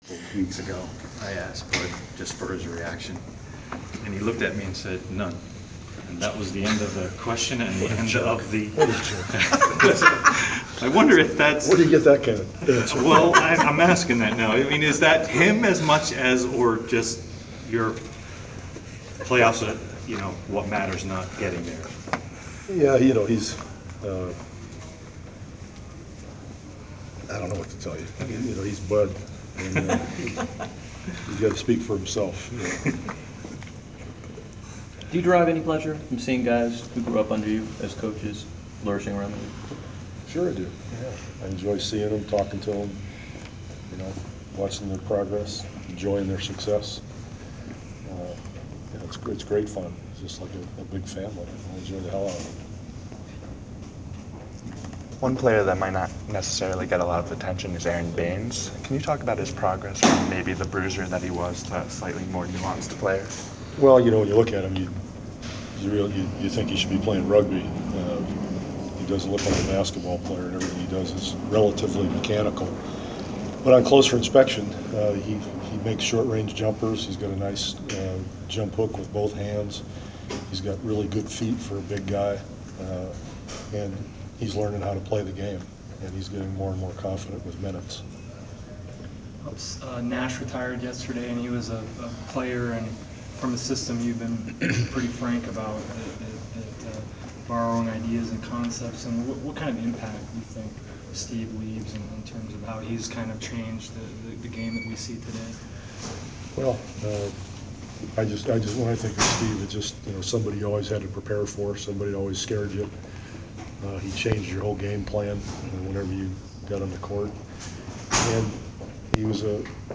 We attended the pregame presser of San Antonio Spurs head coach Gregg Popovich before his team’s road contest against the Atlanta Hawks on Mar. 22. Topics included his coaching philosophies, seeing the success of his former assistants such as current Hawks’ head coach Mike Budenholzer and things the Hawks have done well this season.